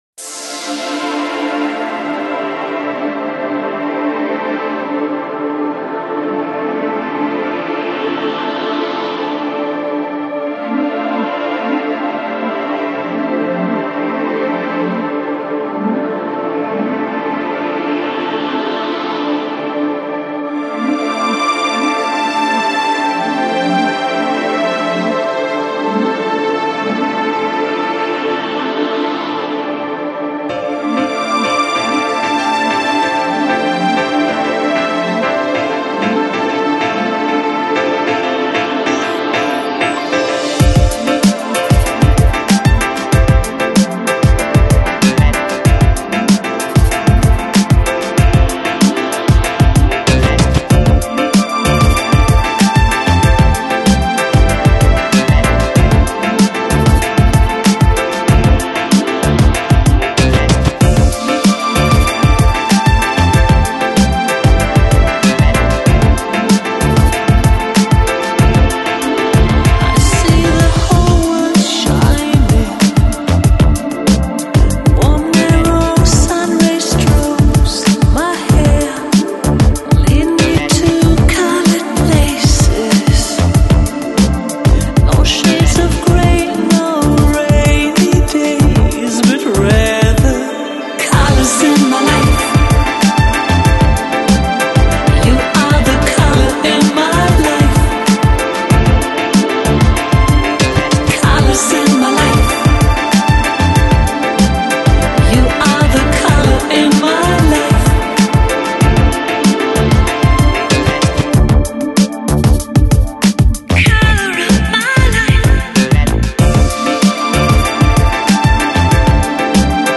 Жанр: Electronic, Lounge, Chill Out, Downtempo, Balearic